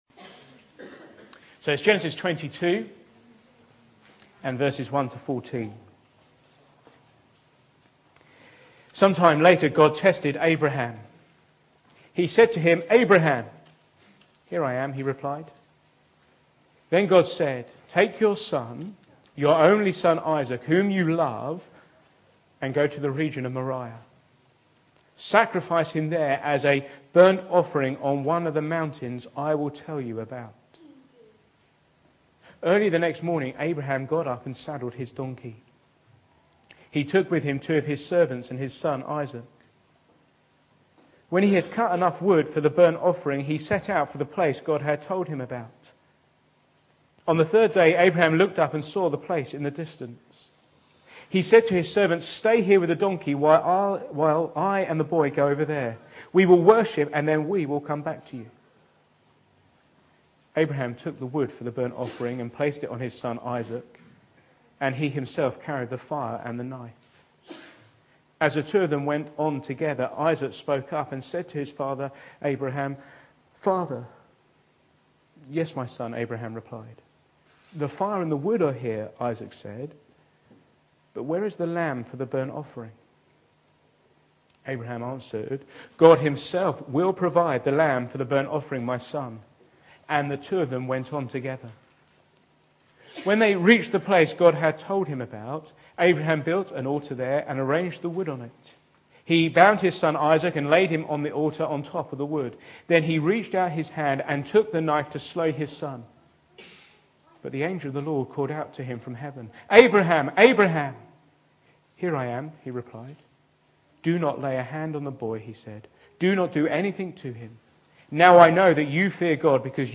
Sermons
Genesis22v1to19_MV_w_interviews.mp3